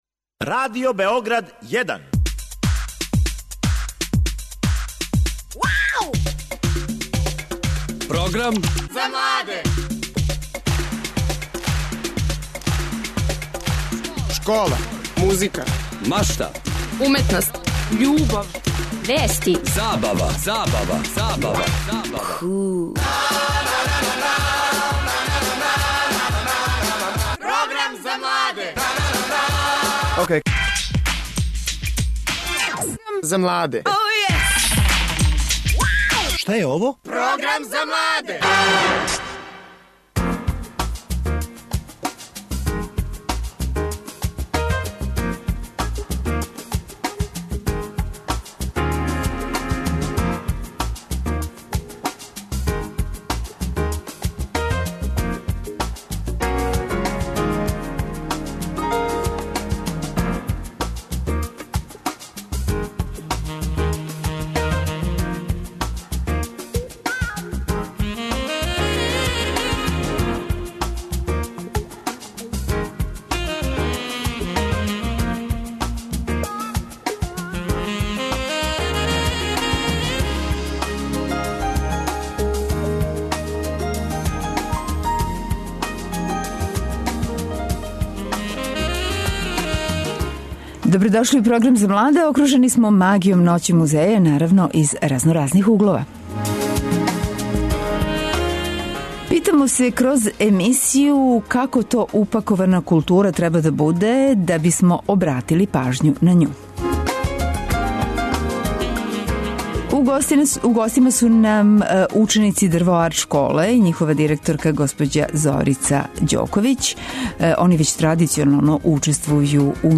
Наши гости који учествују у овој Ноћи музеја, долазе из школе Дрво арт.